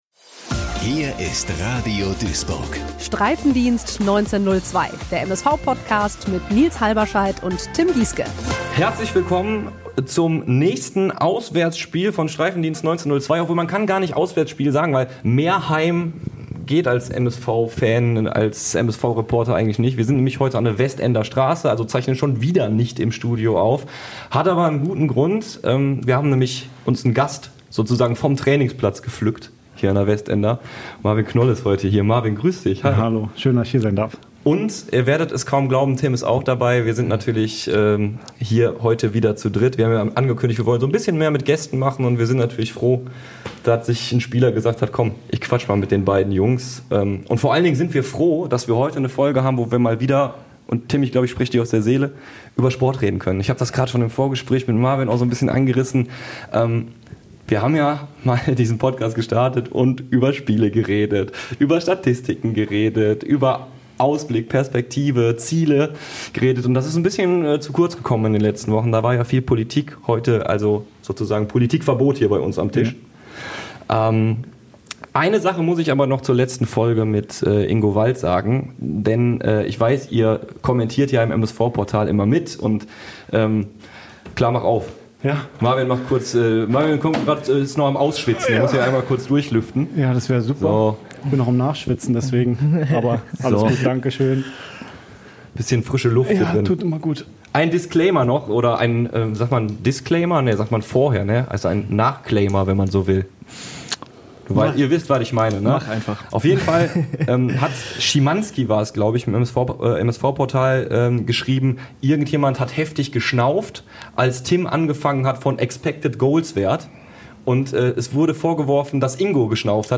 an der Westender Straße im Trainingszentrum des MSV zu Gast